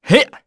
Zafir-Vox_Attack2_kr.wav